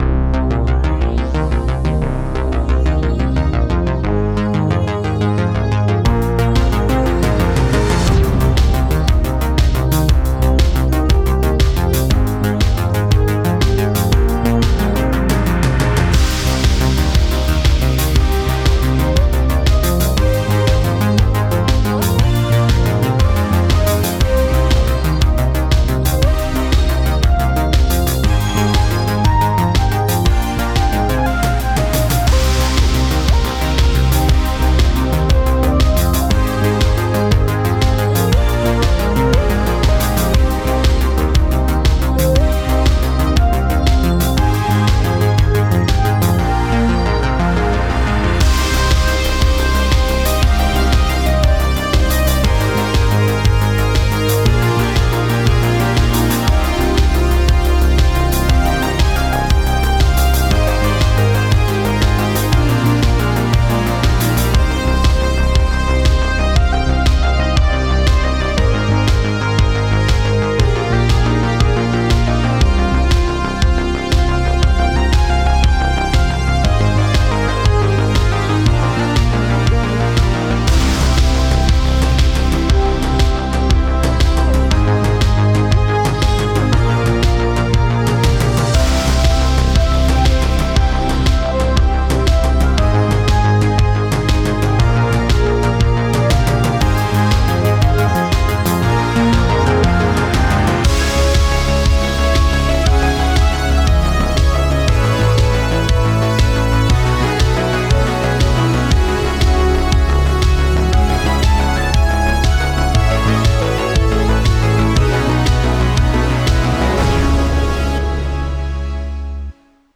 A harmony line?
Pryderi-Theme-Remix.mp3